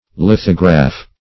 lithograph \lith"o*graph\, n.